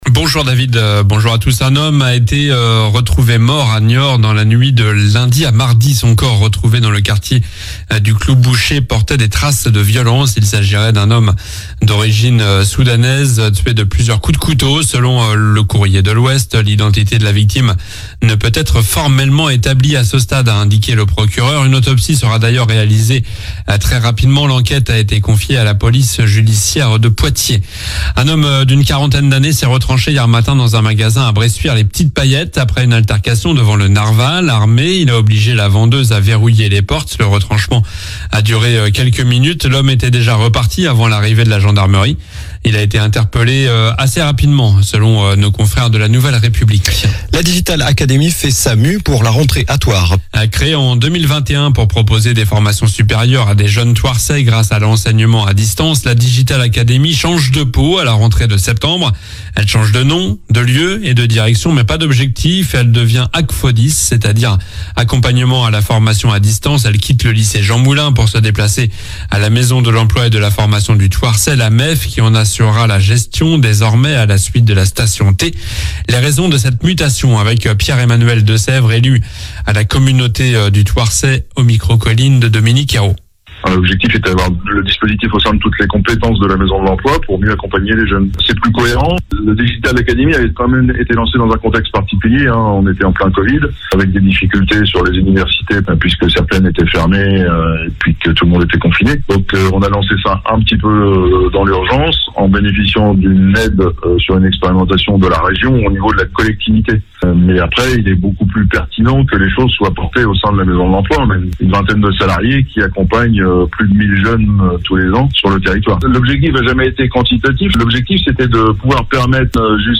Journal du mercredi 24 juillet (matin)